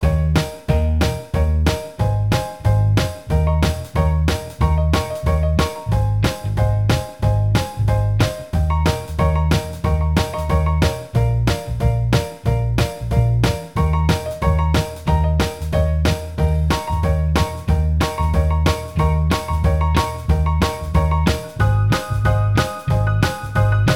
Minus Guitars Rock 'n' Roll 2:27 Buy £1.50